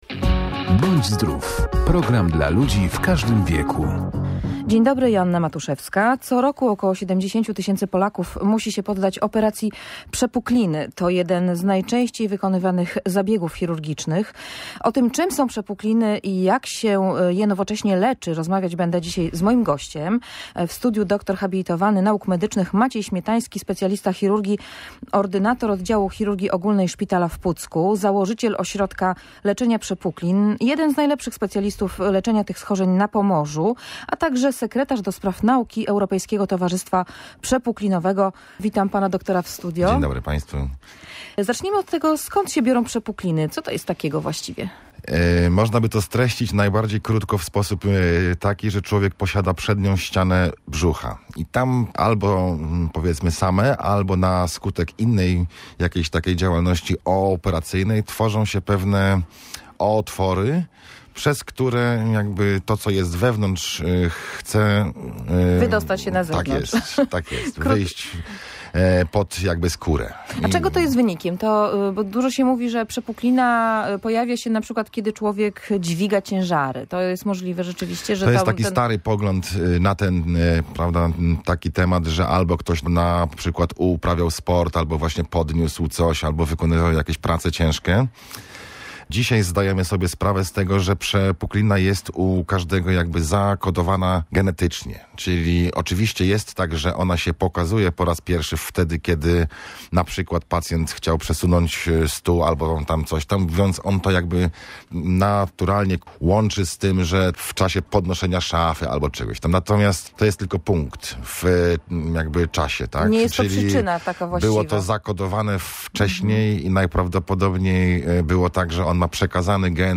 w audycji Bądź Zdrów